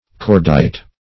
Cordite \Cord"ite\ (k[^o]rd"[imac]t), n. [From Cord, n.]